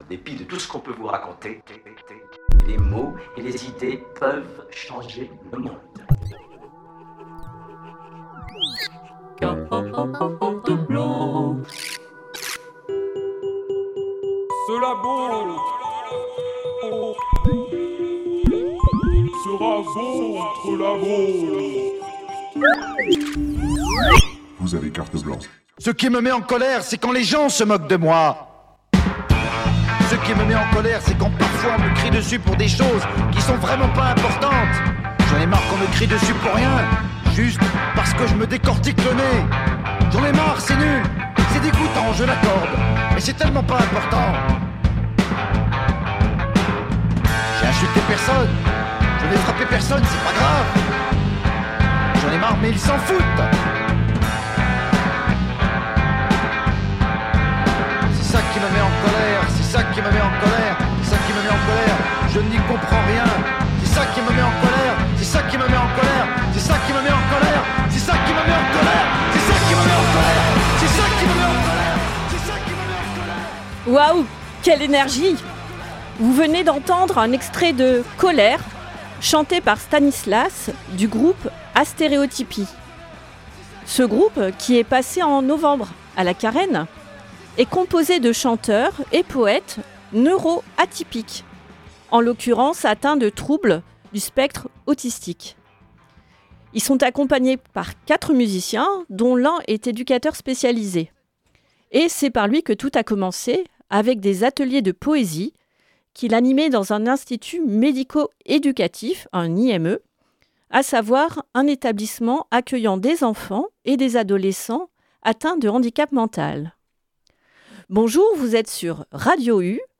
Une émission qui donne la parole à des personnes en situation de handicap qui pratiquent un art (danse, théâtre, musique, chant, peinture, dessin, écriture…), de façon autonome ou par le biais de structures. Pour échanger sur les pratiques artistiques, leurs bénéfices, donner des pistes (lieux, associations,..), et écouter des productions artistiques.